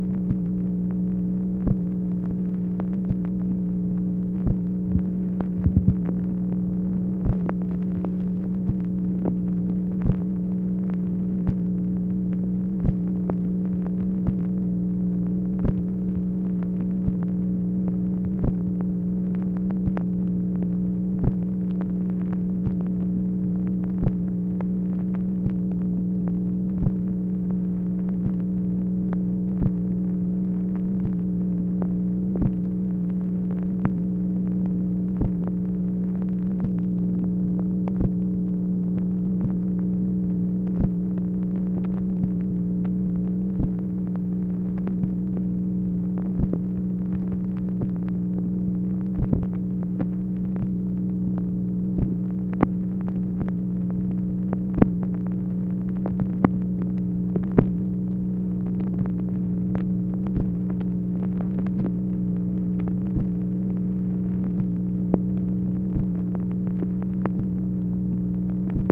MACHINE NOISE, January 24, 1964 | Miller Center
Secret White House Tapes | Lyndon B. Johnson Presidency